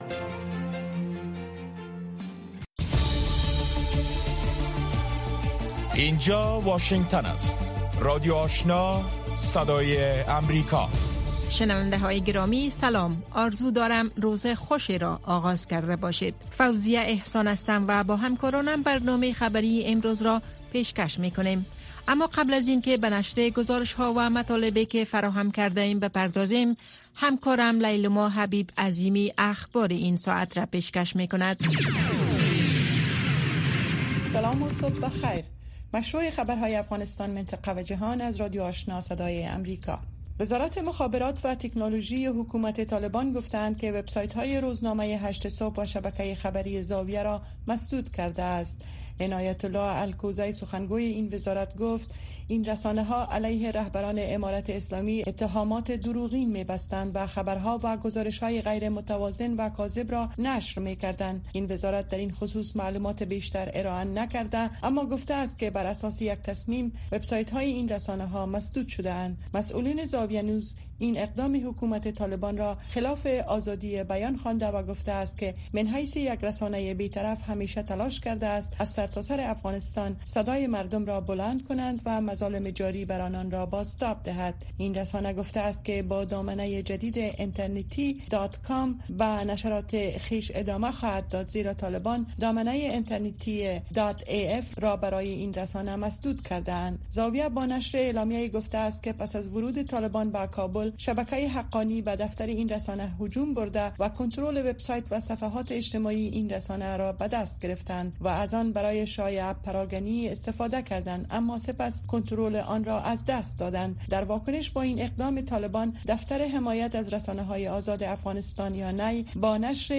برنامۀ خبری صبحگاهی
در برنامۀ صبحگاهی خبرهای تازه از افغانستان و جهان و گزارش‌های تحلیلی و مصاحبه ها در پیوند با رویدادهای داغ افغانستان و جهان به شما پیشکش می شود.